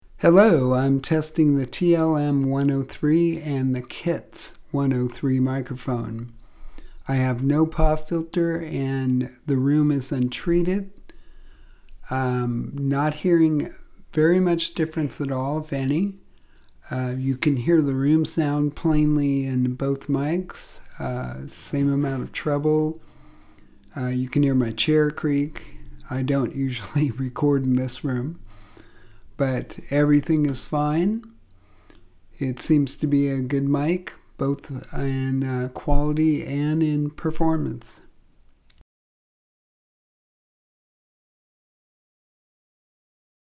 I recorded a test using both the TLM 103 and the KETS 103 at the same time about 3 inches apart - I was in a Untreated room and didn’t use Pop Filter - I would go between them but didn’t hear much if any variance - although the KETS mic has a feint upper treble ringing to my ears - I know which mic was which in the recording - but in a blind test I doubt I could tell - the audio is solid and I would gladly use this mic in a critical setting.